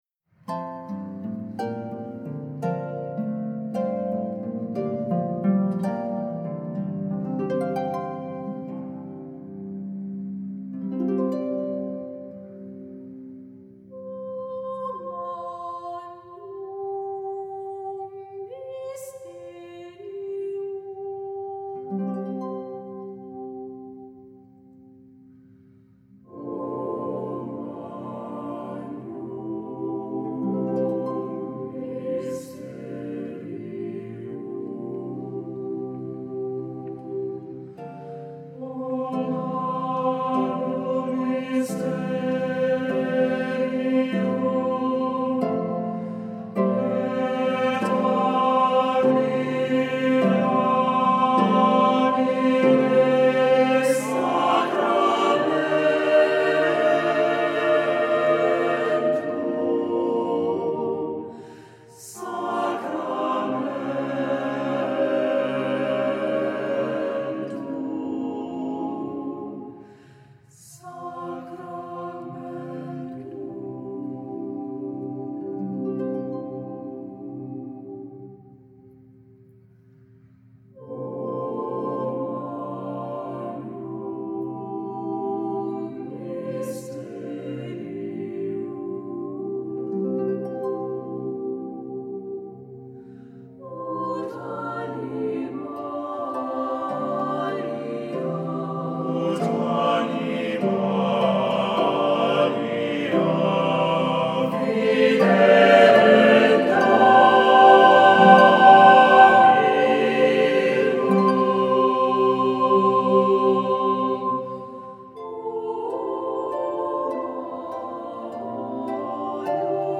Music Category:      Choral